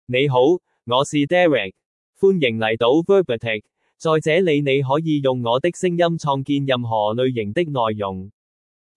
MaleChinese (Cantonese, Hong Kong)
DerekMale Chinese AI voice
Derek is a male AI voice for Chinese (Cantonese, Hong Kong).
Voice sample
Listen to Derek's male Chinese voice.
Derek delivers clear pronunciation with authentic Cantonese, Hong Kong Chinese intonation, making your content sound professionally produced.